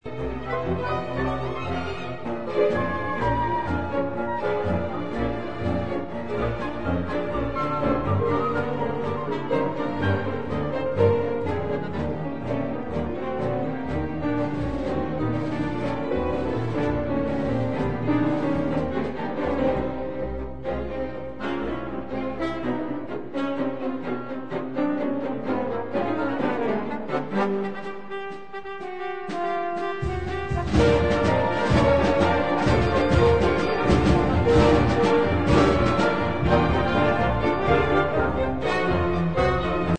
پیانو، بادی های چوبی و بادی های برنجی برای جلب توجه شنونده رقابت می کنند و چندان نمی گذرد که گویی دو دسته همنواز، همزمان در تقابل با یکدیگر می نوازند.